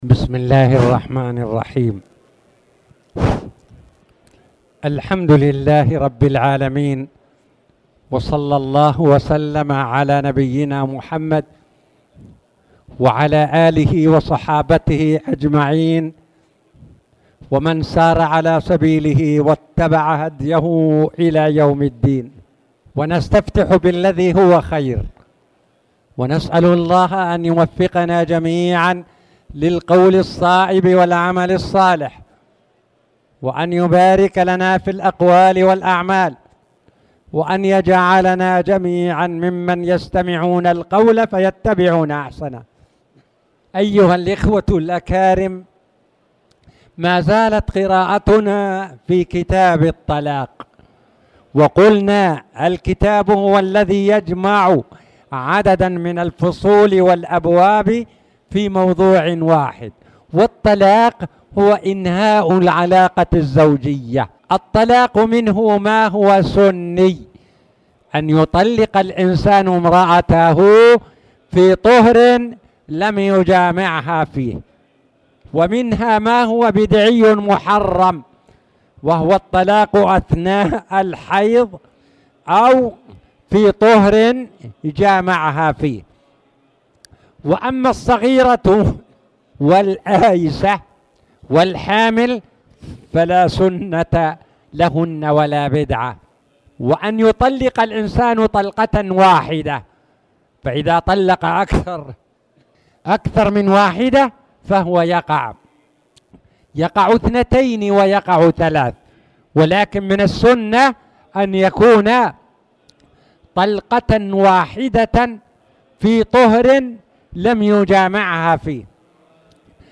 تاريخ النشر ٢٧ جمادى الآخرة ١٤٣٨ هـ المكان: المسجد الحرام الشيخ